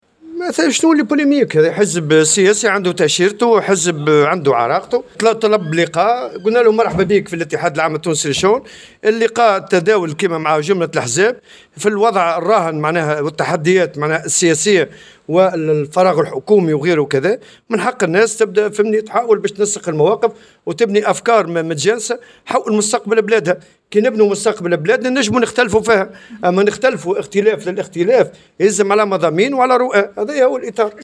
قال الأمين العام للاتحاد العام التونسي للشغل نور الدين الطبوبي، في تصريح للجوهرة أف أم، اليوم السبت، إن الحزب الدستوري الحر هو من طلب اللقاء بالمنظمة الشغيلة التي رحبت بذلك باعتباره حزبا له مكانة في المشهد السياسي.
وأكد الطبوبي لدى إشرافه على إحياء يوم العلم، بالمنستير، أن اللقاء الذي يندرج في إطار جملة اللقاءات التي يعقدها الاتحاد مع مختلف الأحزاب، قد تطرق للوضع السياسي الراهن وجملة التحديات التي تواجه البلاد، بهدف تنسيق المواقف وبناء أفكار متجانسة حول مستقبل البلاد.